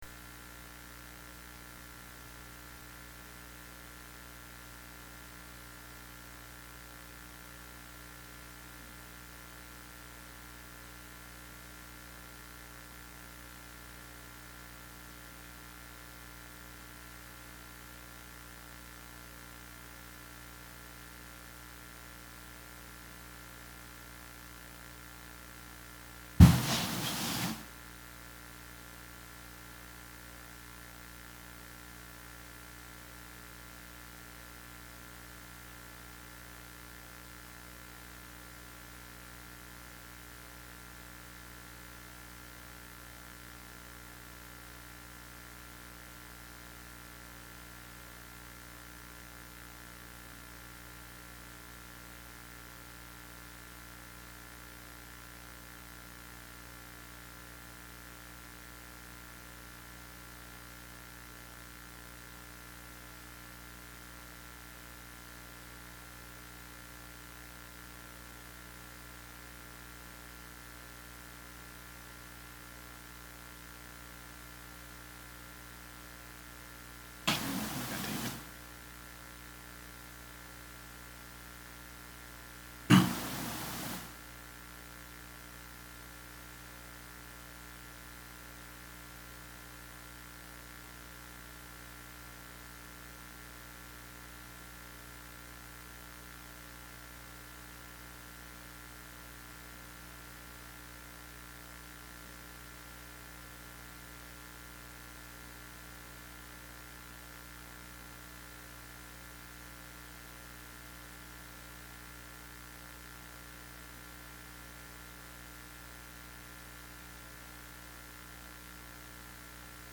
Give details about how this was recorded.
Vendor Conference Recording